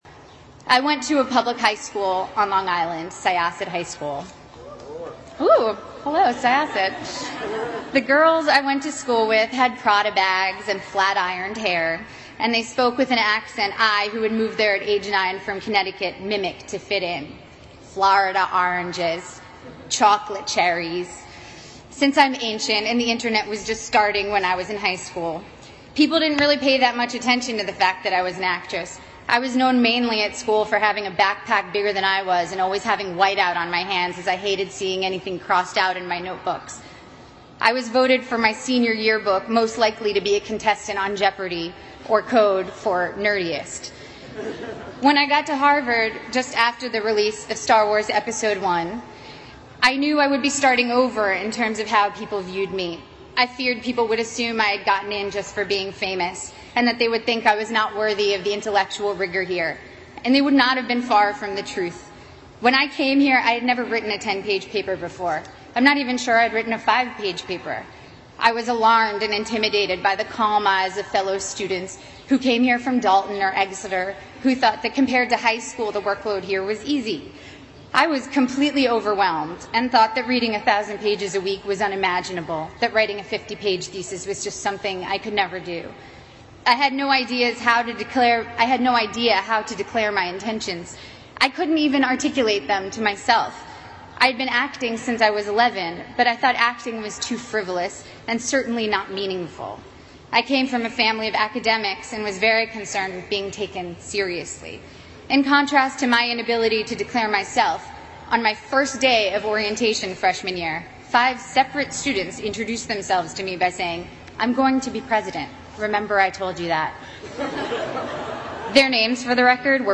在线英语听力室娜塔莉波特曼哈佛大学演讲 第3期的听力文件下载,哈佛牛津名人名校演讲包含中英字幕音频MP3文件，里面的英语演讲，发音地道，慷慨激昂，名人的效应就是激励他人努力取得成功。
娜塔莉波特曼2015哈佛毕业典礼演讲，王力宏在牛津大学的演讲以及2013年奥普拉在哈佛大学的毕业演讲等名人在哈佛大